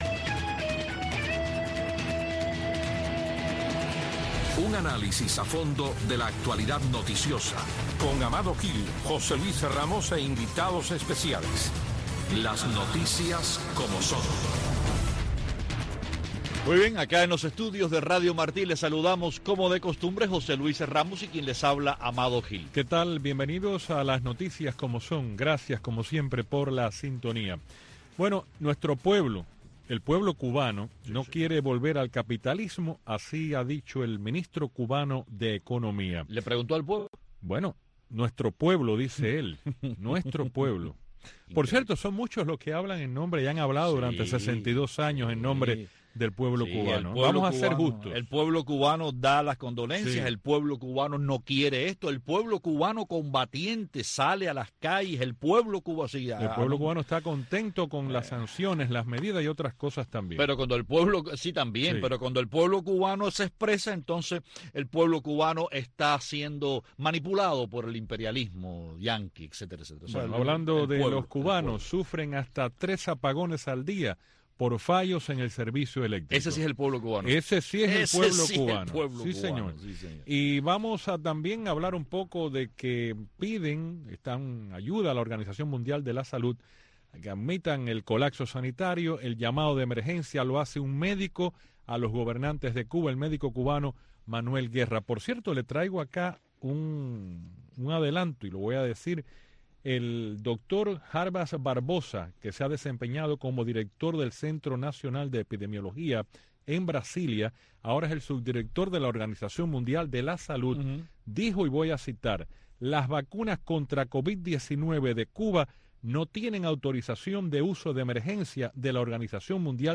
Una discusión dinámica y a fondo de las principales noticias del acontecer diario de Cuba y el mundo, con la conducción de los periodistas